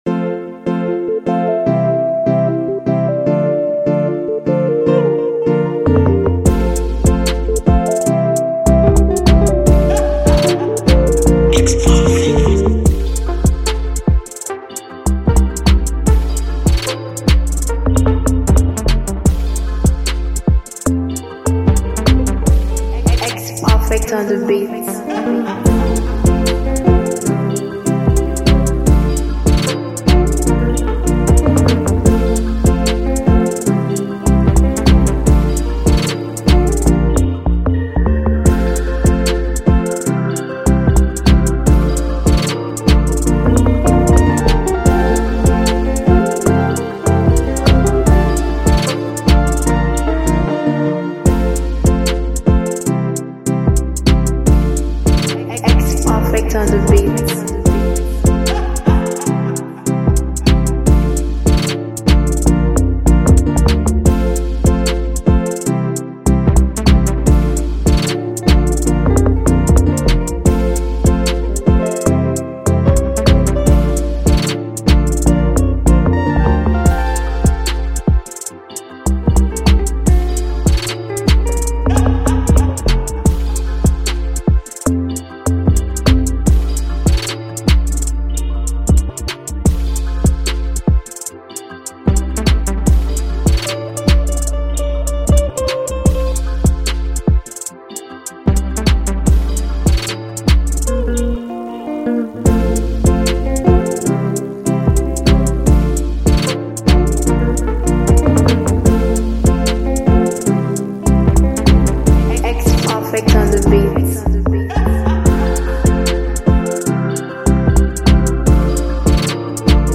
Download instrumental mp3 below….